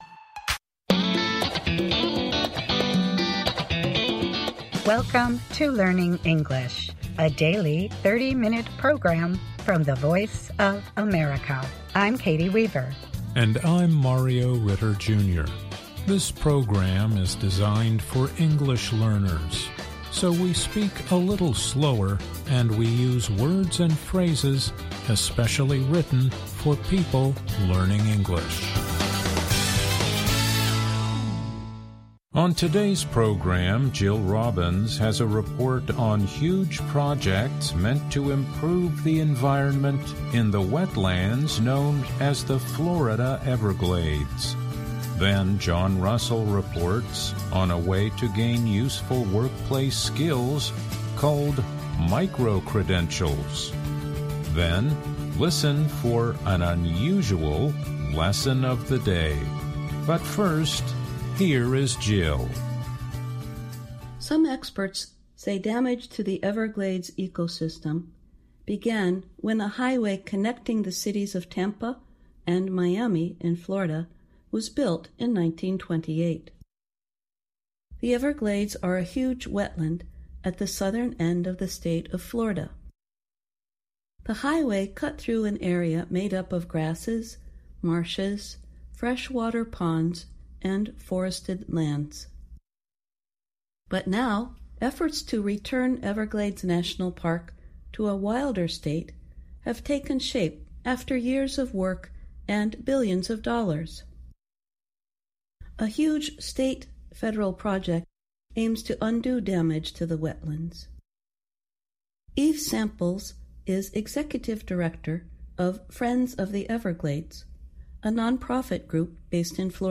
Learning English programs use a limited vocabulary and short sentences. They are read at a slower pace than VOA's other English broadcasts.